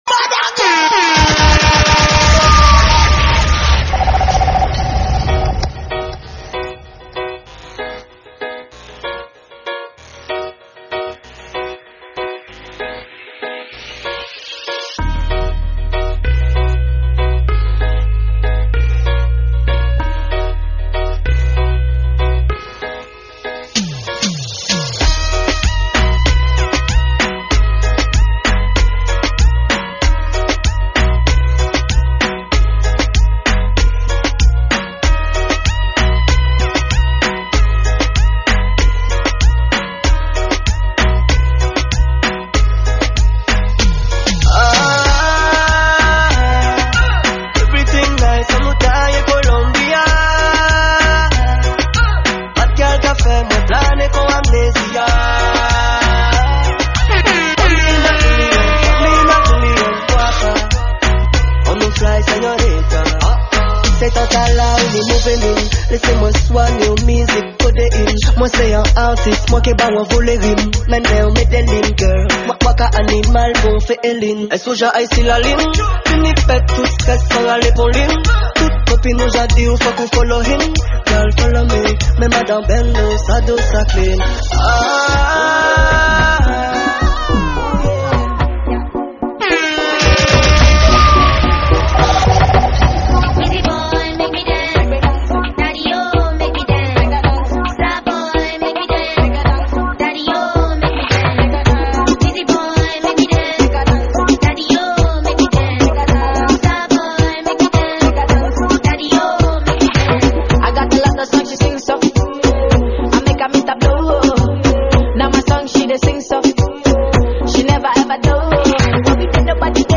Genre : MIXES